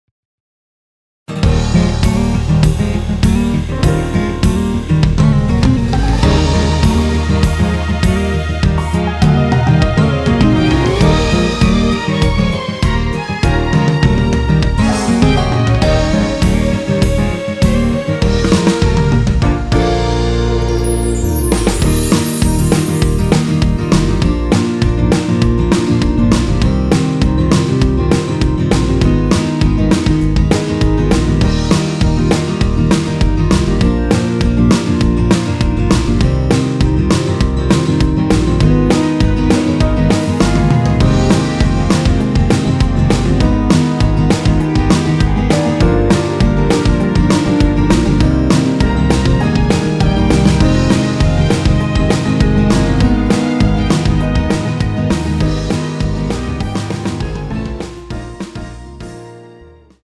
원키에서(-3)내린 멜로디 포함된 MR입니다.(미리듣기 확인)
Eb
앞부분30초, 뒷부분30초씩 편집해서 올려 드리고 있습니다.